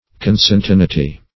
Consentaneity \Con*sen`ta*ne"i*ty\, n. Mutual agreement.